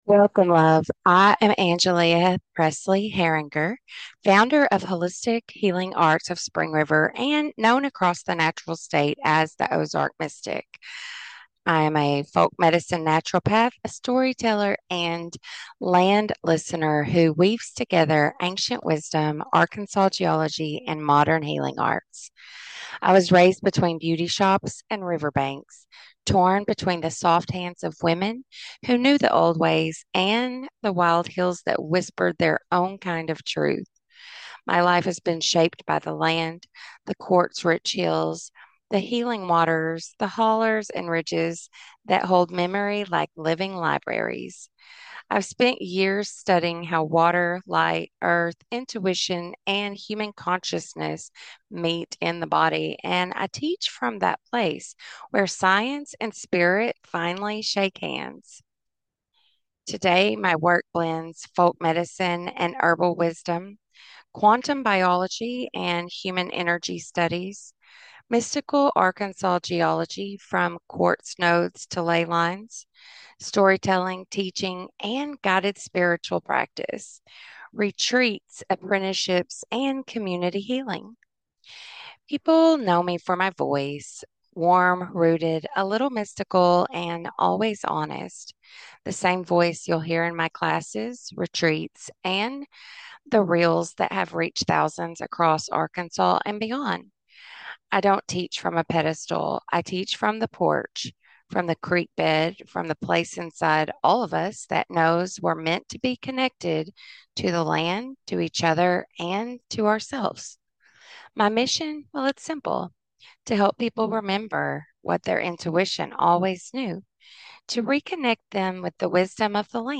Welcome-1125.mp3